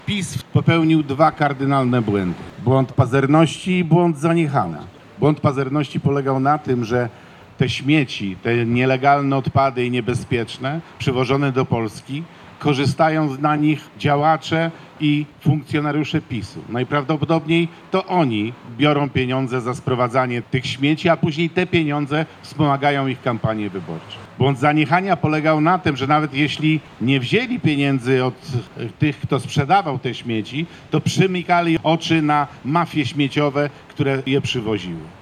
W trakcie protestu pod siedzibą Prawa i Sprawiedliwości w Szczecinie głos w sprawie nielegalnych składowisk śmieci zabrał poseł Artur Łącki.